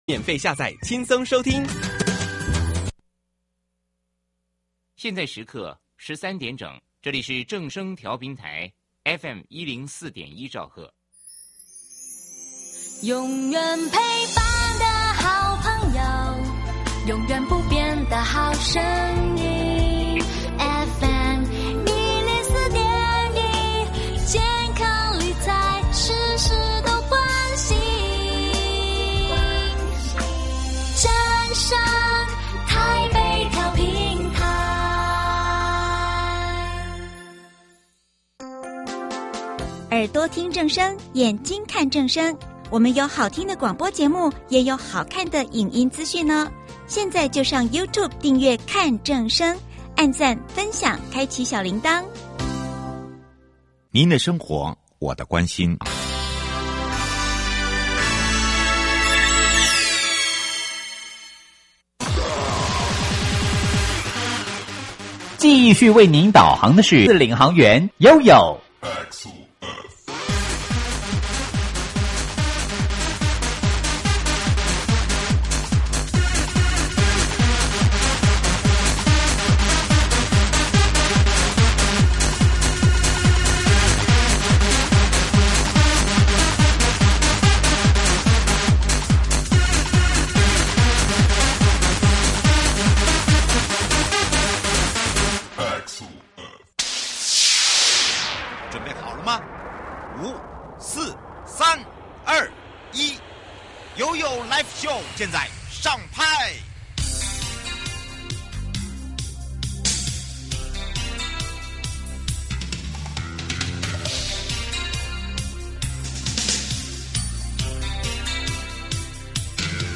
受訪者： 營建你我他 快樂平安行~七嘴八舌講清楚~樂活街道自在同行!